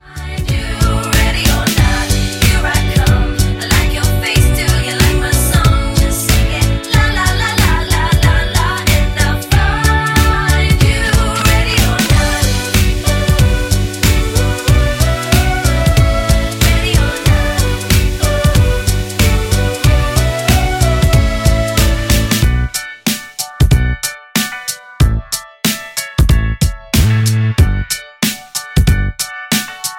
MPEG 1 Layer 3 (Stereo)
Backing track Karaoke
Pop, 2010s